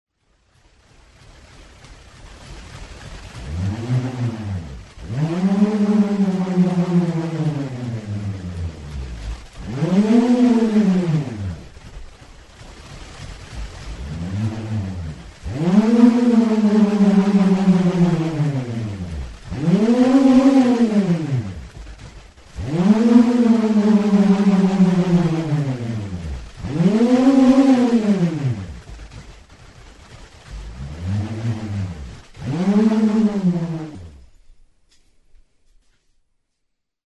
Aerophones -> Free-vibrating
Recorded with this music instrument.
Zurezko oholtxo bat da.